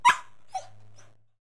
Bark5.wav